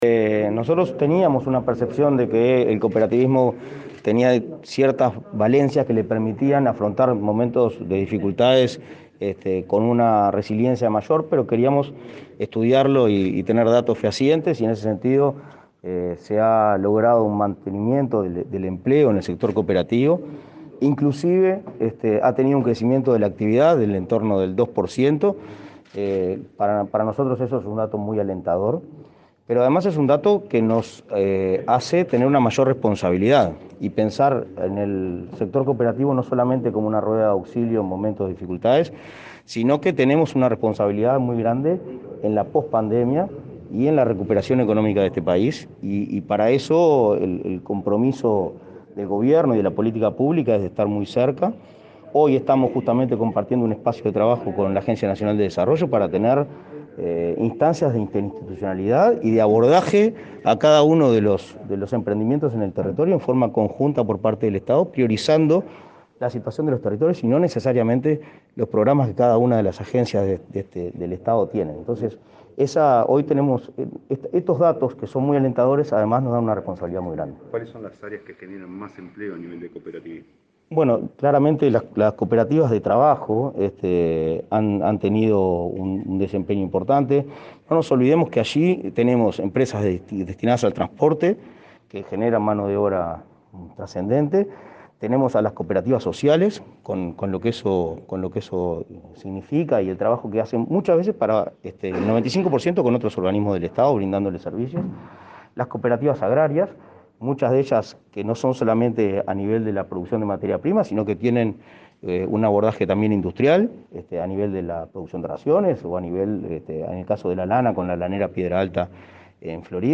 Declaraciones a la prensa del presidente del Inacoop, Martín Fernández | Presidencia Uruguay
Declaraciones a la prensa del presidente del Inacoop, Martín Fernández 27/08/2021 Compartir Facebook X Copiar enlace WhatsApp LinkedIn Este viernes 27, el presidente del Instituto Nacional del Cooperativismo (Inacoop), Martín Fernández, participó de un desayuno de trabajo organizado por la Agencia Nacional de Desarrollo (ANDE), para evaluar las acciones realizadas en el marco del Programa de Mejora de Gestión de ese instituto, y, luego, dialogó con la prensa.